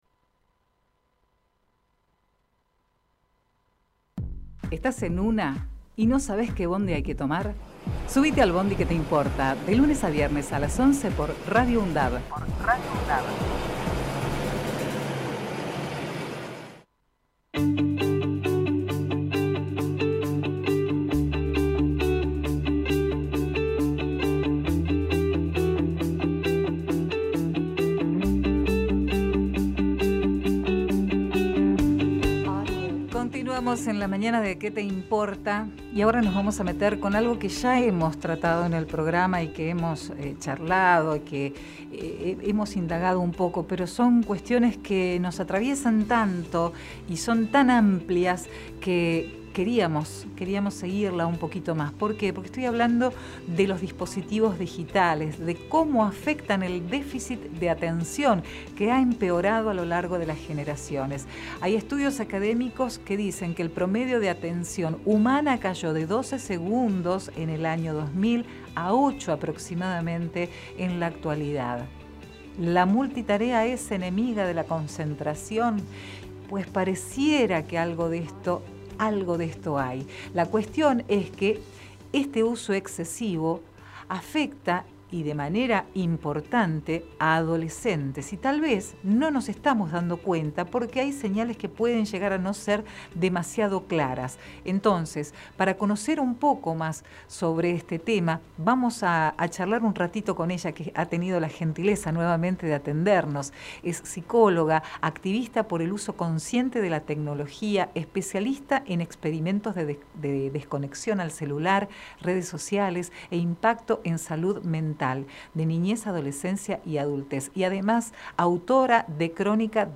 Compartimos la entrevista